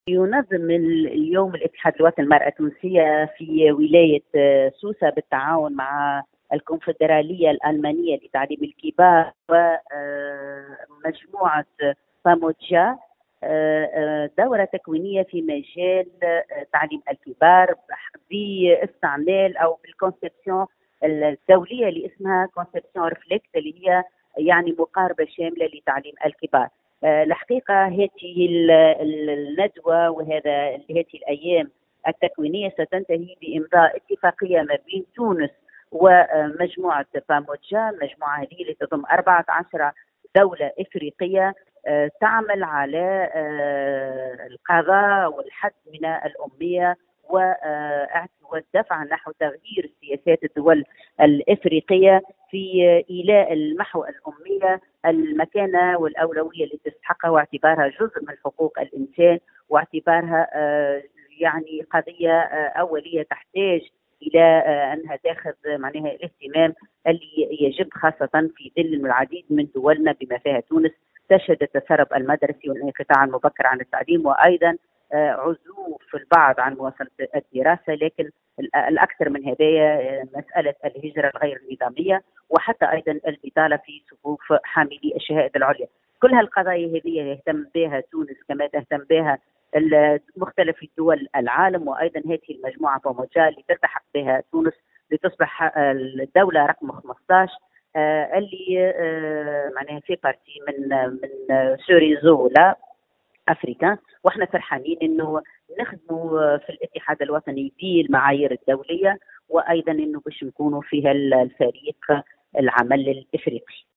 في تصريح للجوهرة أف أم